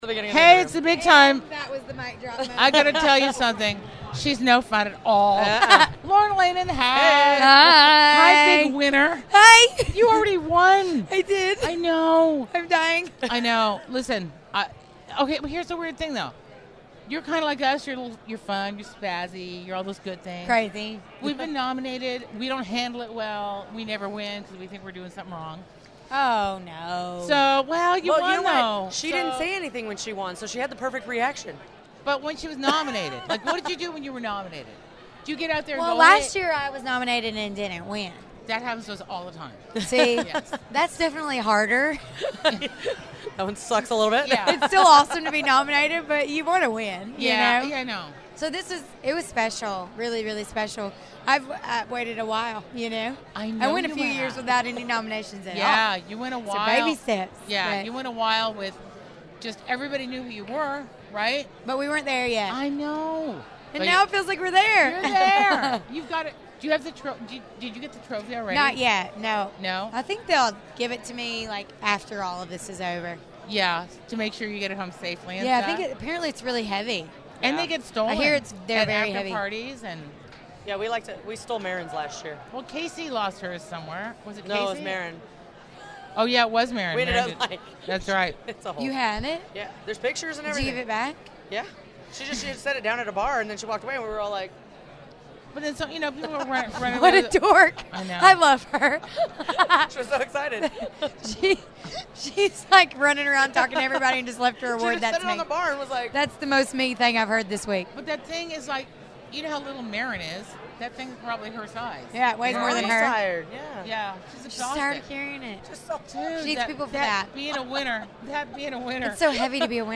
Lauren Alaina Interview At 2018 ACMs!